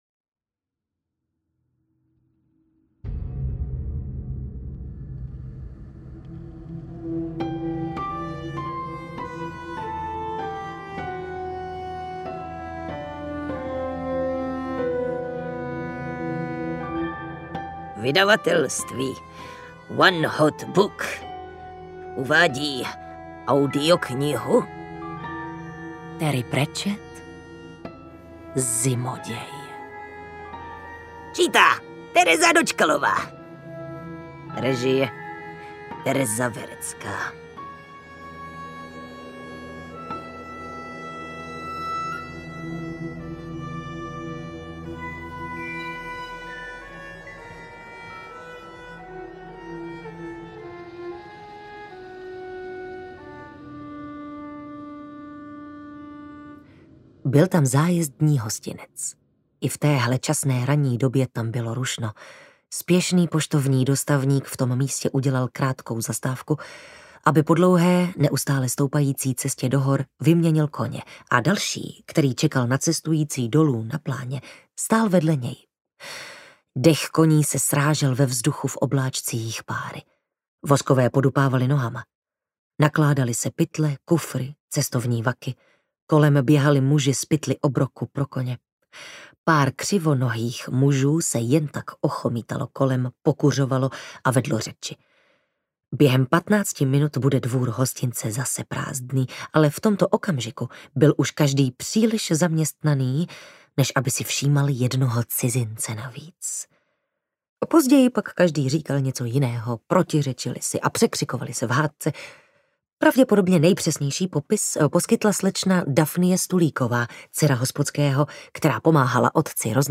Zimoděj audiokniha
Ukázka z knihy